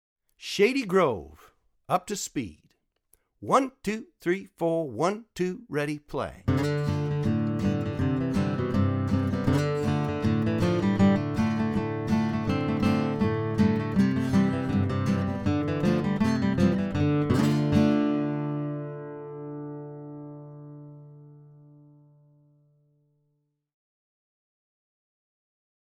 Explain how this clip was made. Online Audio (both slow and regular speed)